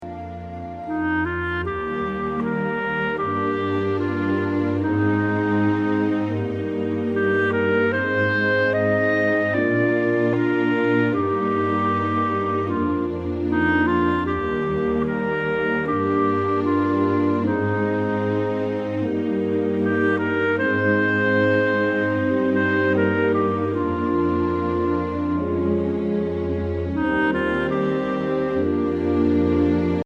Voicing: Bb Clarinet